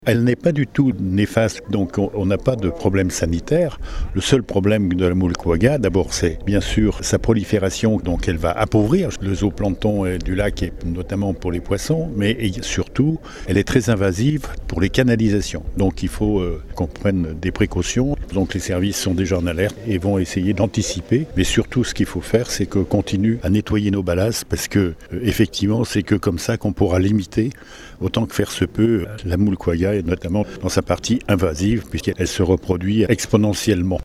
Les explications de Pierre Bruyère, Président du Sila, le syndicat intercommunal du lac d’Annecy.